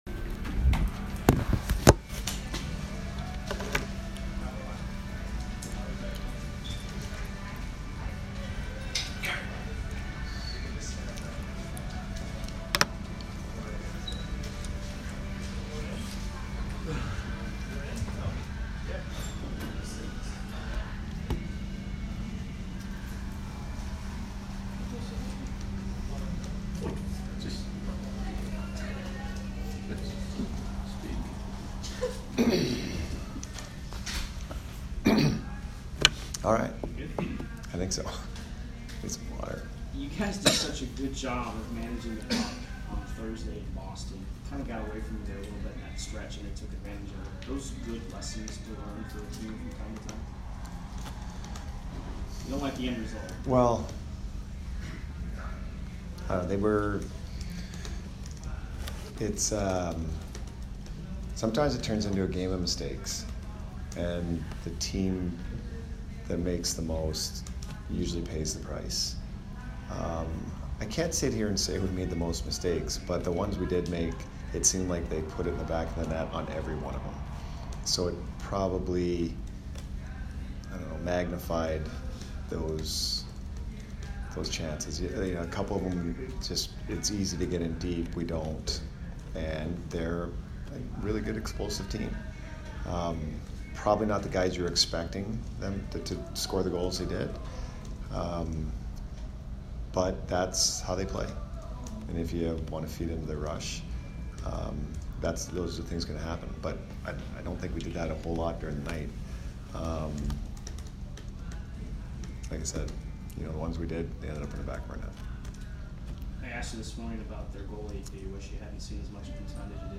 Jon Cooper post-game 10/19